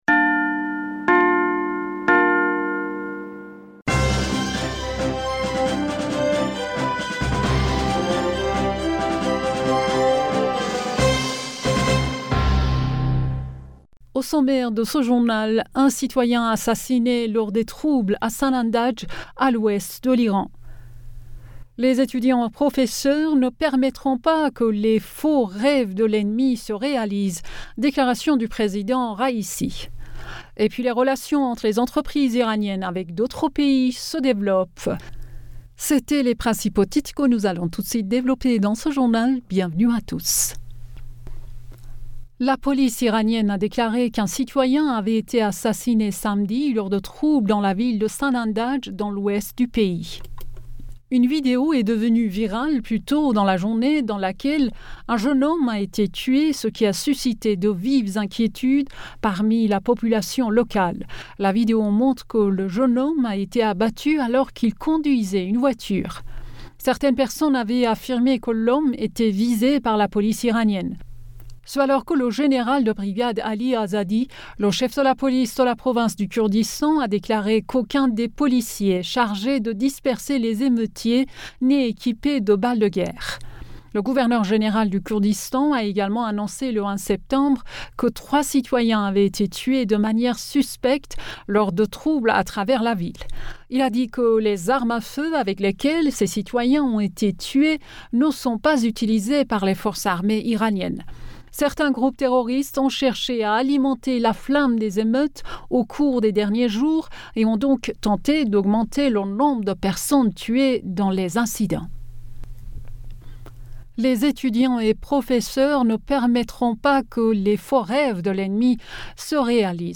Bulletin d'information Du 09 Octobre